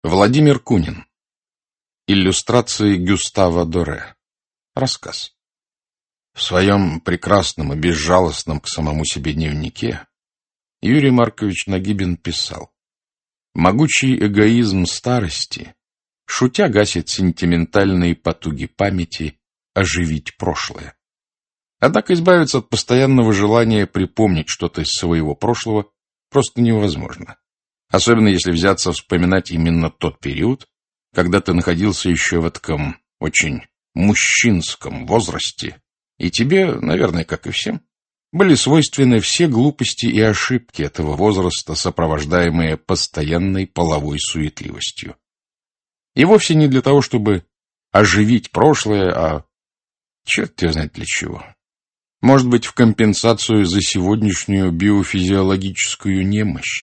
Aудиокнига Иллюстрации Гюстава Доре Автор Владимир Кунин Читает аудиокнигу Александр Клюквин.